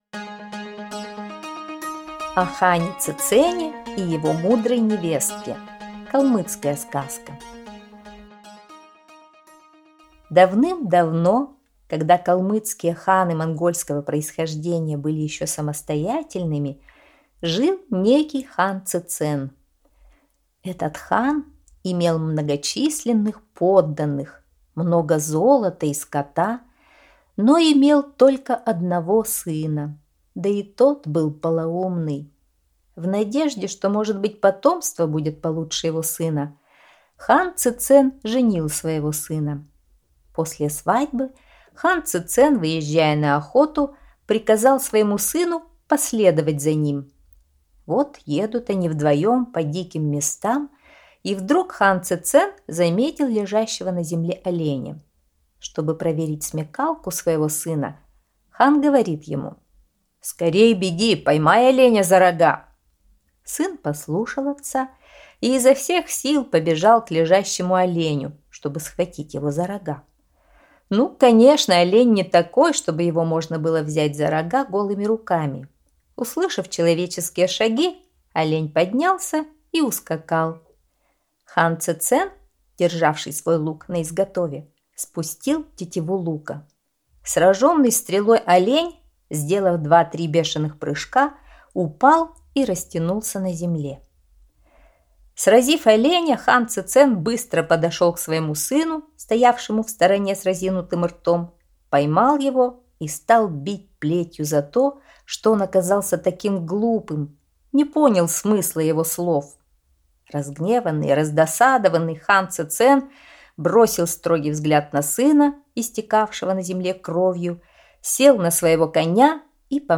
О хане Цецене и его мудрой невестке - калмыцкая аудиосказка